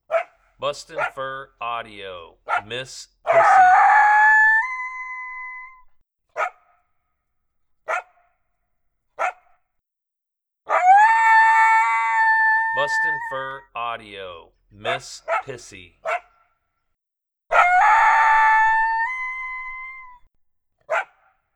Female Coyote Beans responding aggressively to howls. Lots of barking and aggressive howling in this sound.